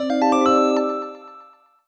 jingle_chime_12_positive.wav